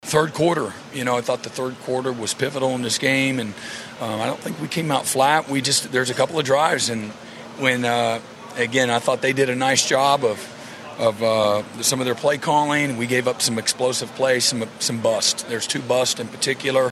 Sooner head coach Brent Venables talks about the game.